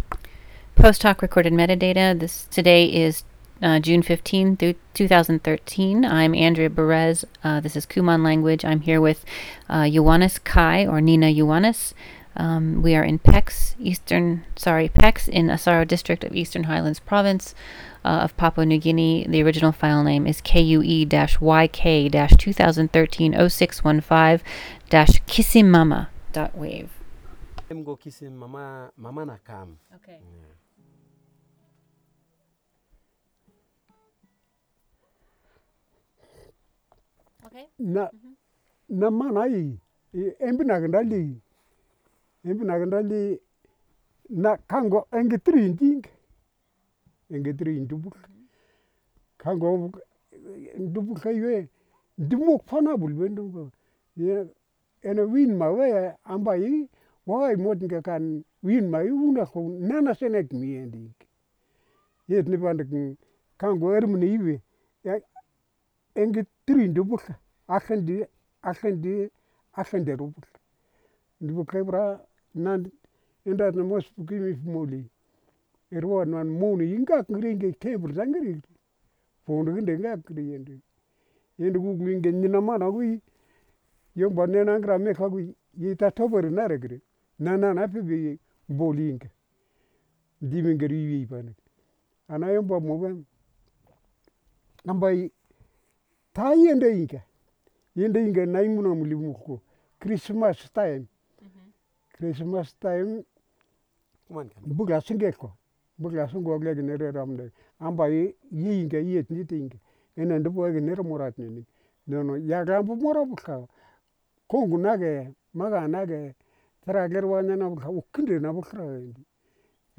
digital wav file recorded at 44.1 kHz/16 bit on Zoom H4n solid state recorder with Countryman e6 headset microphone
Pex Village, Asaro District, Eastern Highlands Province, Papua New Guinea